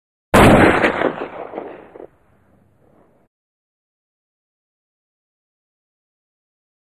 Rifle Shot With Echo.